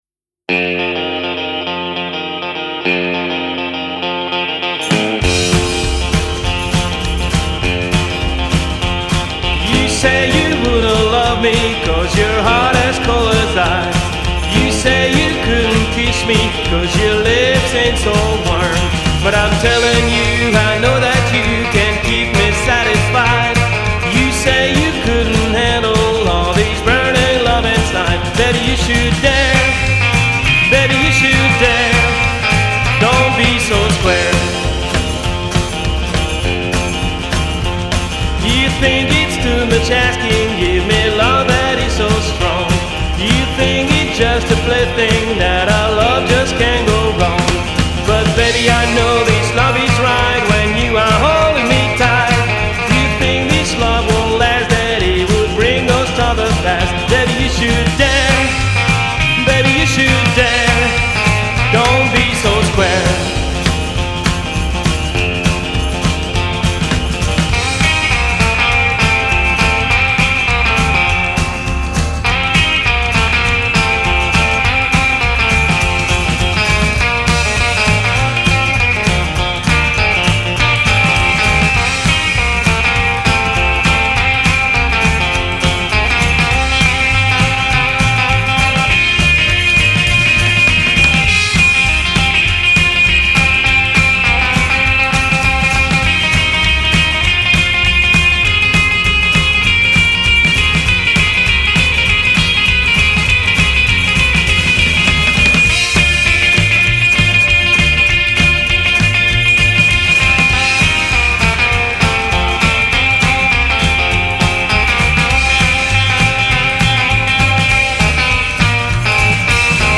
WILD ROCKABILLY FROM BOURGES
DESPERATE 50'  R'n'R SOUND .... !
Lead Vocal /Rhythm Guitar
Lead Guitar
Batterie
Contrebasse